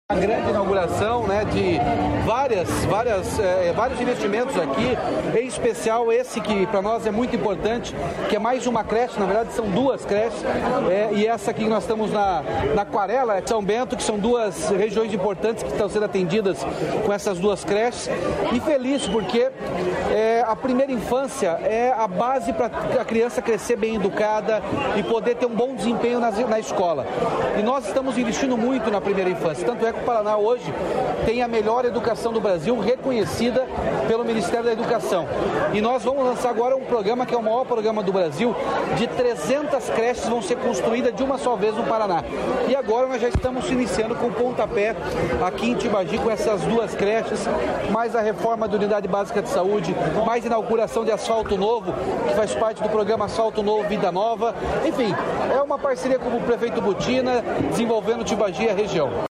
Sonora do governador Ratinho Junior sobre a entrega dos centros de educação infantil em Tibagi